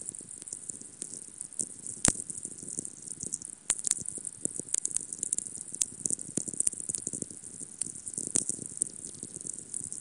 Атмосферики Земли: тайны низкочастотных электромагнитных волн